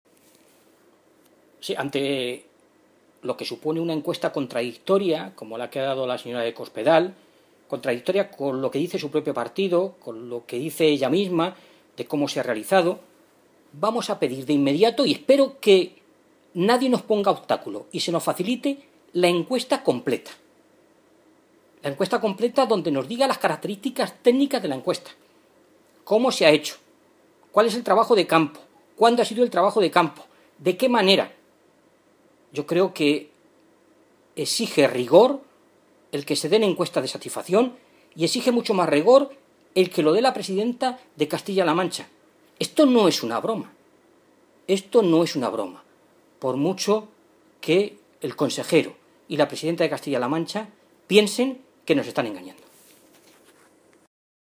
Fernando Mora, portavoz de Sanidad del Grupo Parlamentario Socialista
Cortes de audio de la rueda de prensa